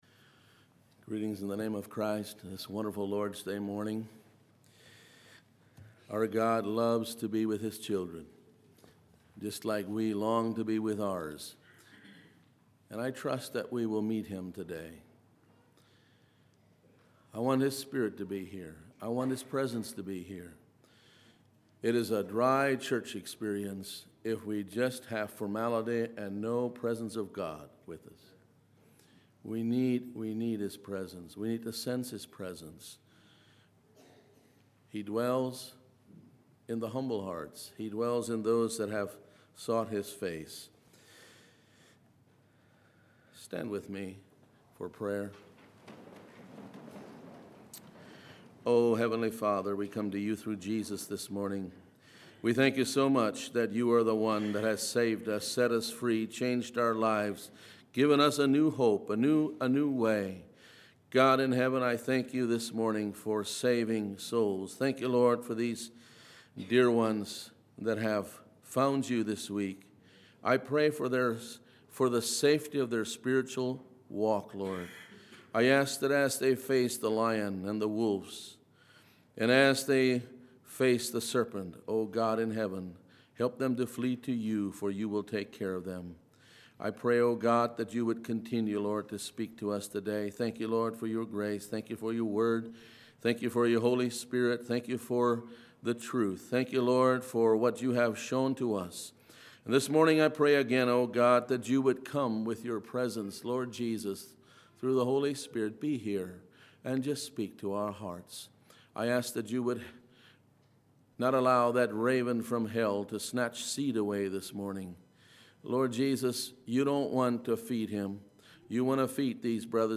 Sermons
Congregation: Mount Joy